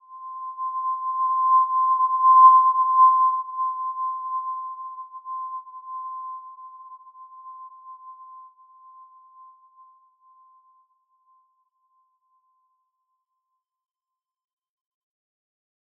Simple-Glow-C6-f.wav